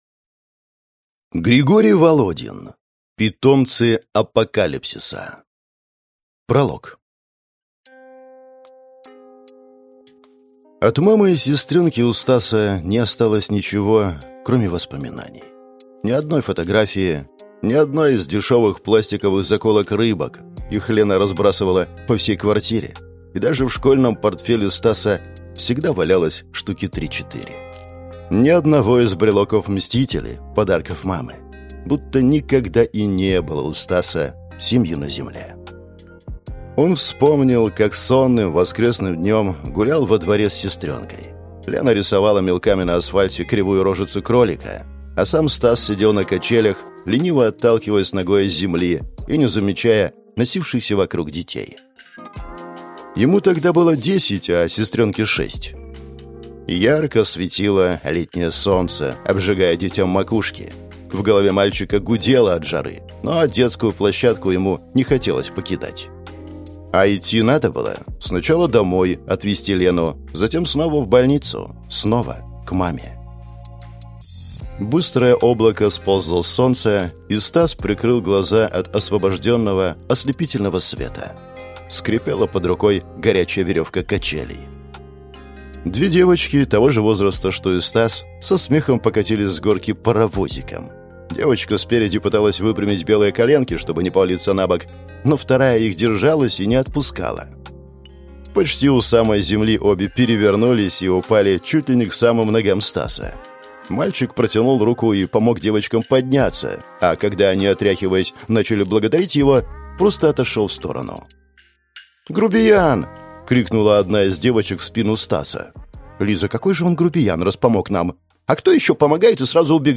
Аудиокнига Питомцы апокалипсиса | Библиотека аудиокниг